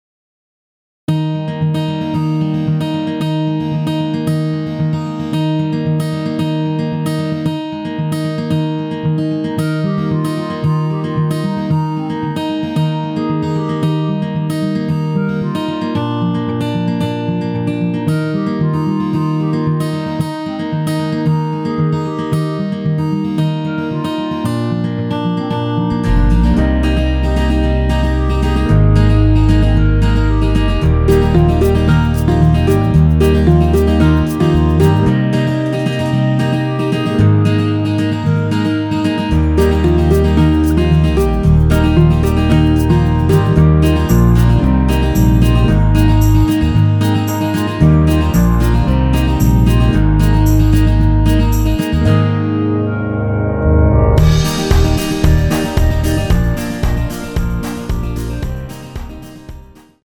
원키에서(-2)내린 멜로디 포함된 MR입니다.(미리듣기 확인)
Ab
앞부분30초, 뒷부분30초씩 편집해서 올려 드리고 있습니다.